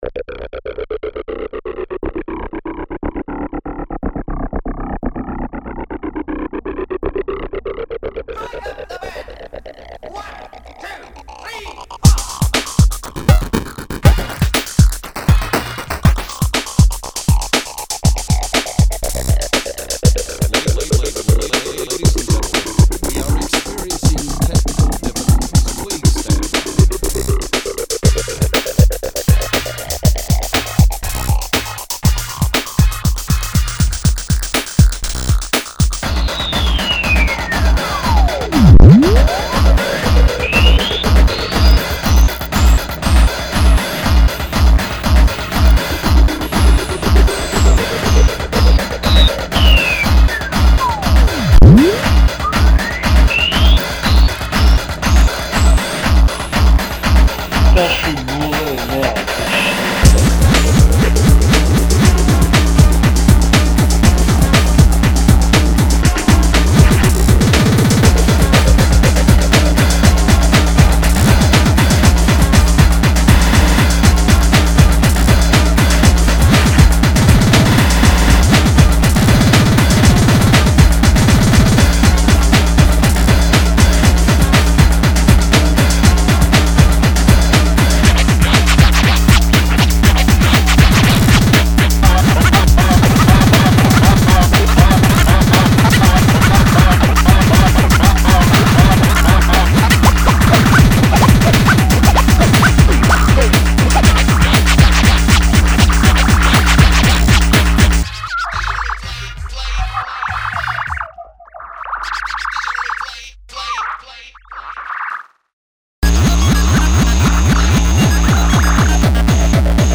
Nouvelle track hardcore débile